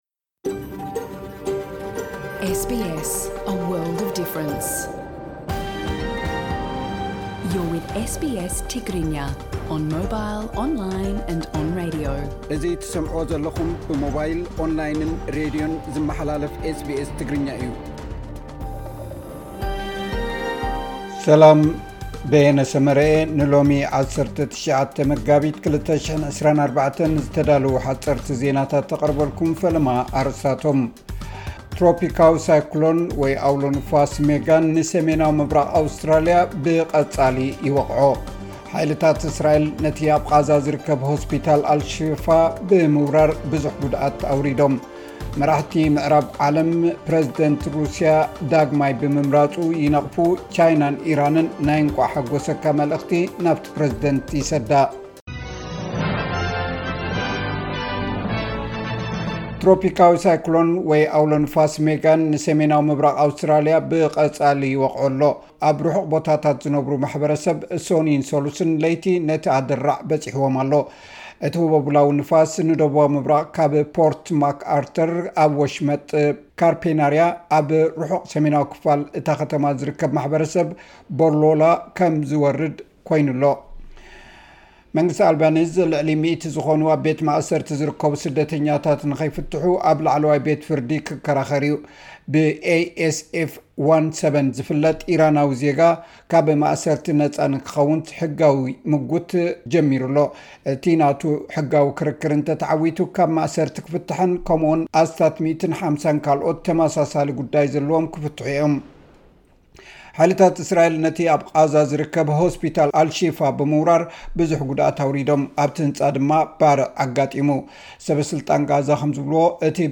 ሓጸርቲ ዜናታት ኤስ ቢ ኤስ ትግርኛ (19 መጋቢት 2024)